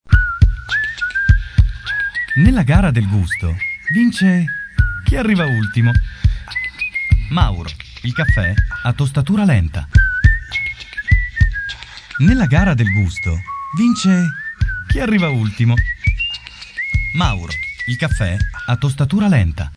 Demo Audio Pubblicità Voiceover